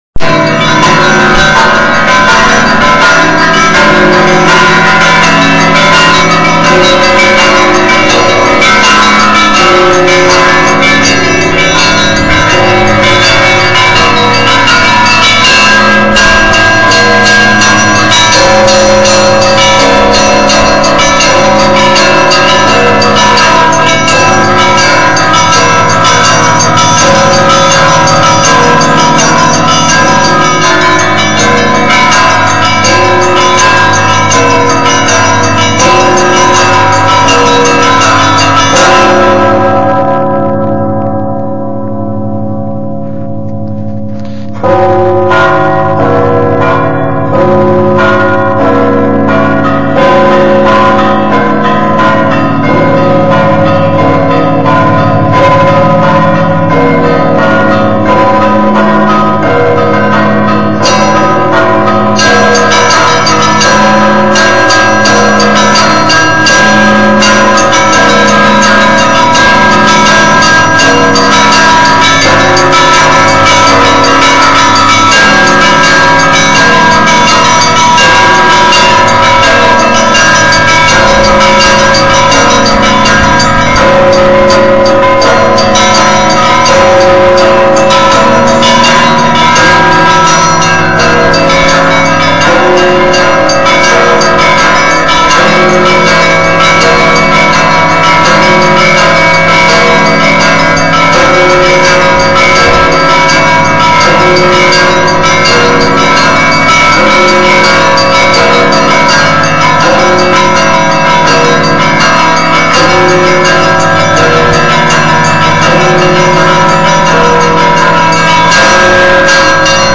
zvonari-optiny-zvon-na-rozhdestvenskiy-sochel-nik-2.mp3